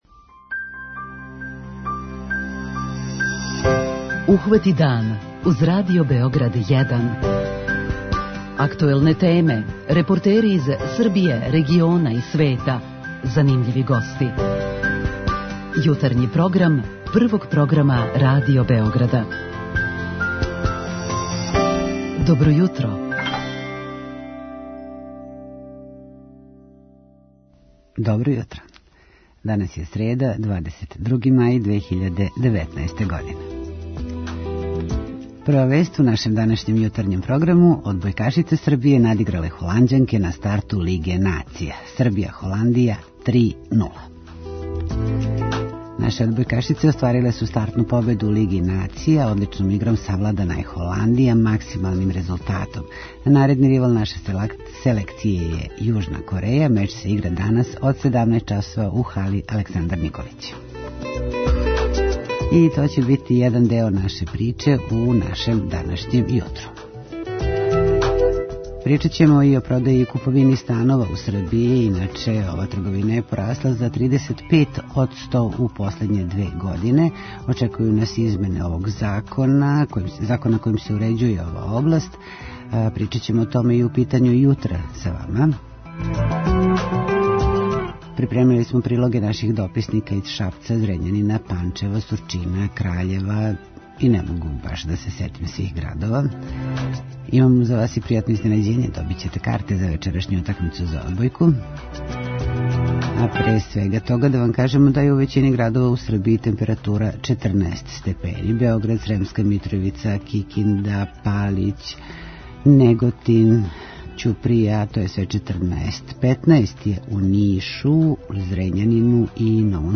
Продаја и куповина станова у Србији је порасла за 35 одсто у последње две године, саопштила је Групација поседника у промету непокретности Србије и најавила измене закона којим се уређује ова област. Тим поводом, долази нам гост из Привредне коморе Србије.
Наши дописници и репортери ће се јавити из Шапца, Зрењанина, Панчева, Сурчина и Краљева.